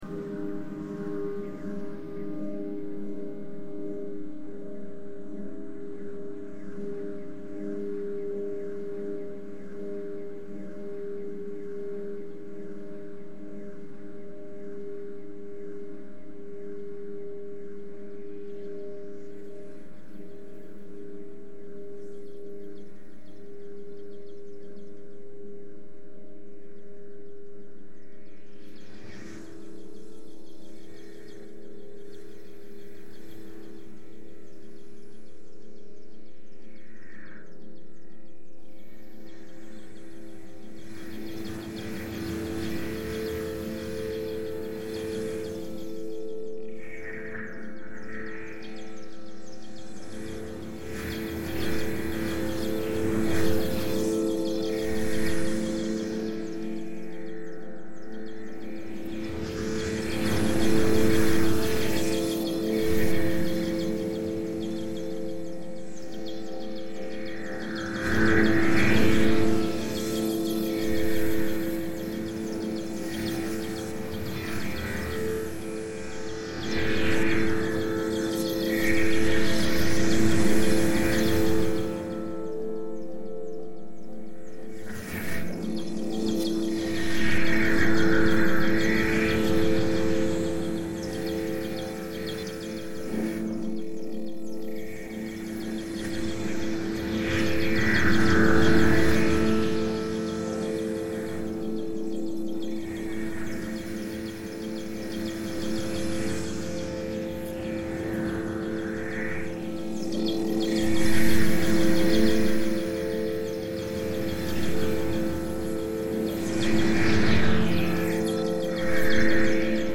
Natural soundscape on Monte Venda reimagined